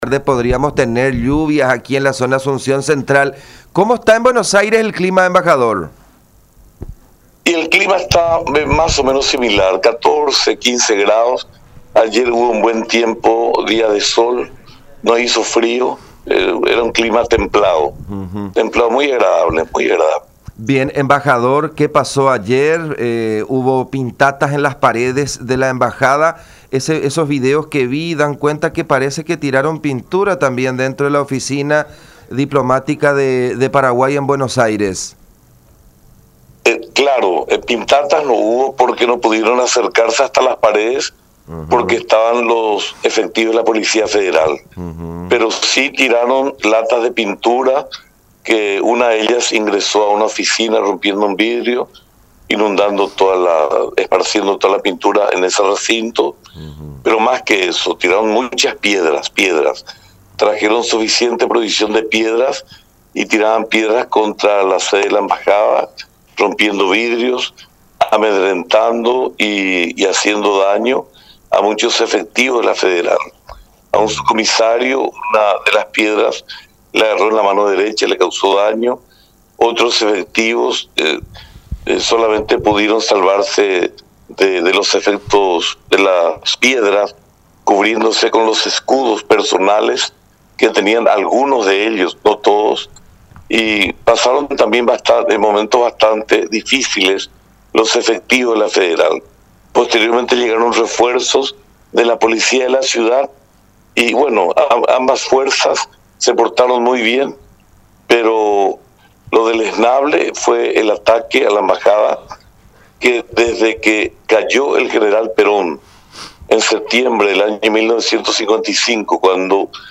Fueron momentos bastante tensos los que se vivieron ayer, incluso hubo policías heridos en esa manifestación”, aseveró el embajador paraguayo en Argentina, Julio César Vera, en contacto con La Unión.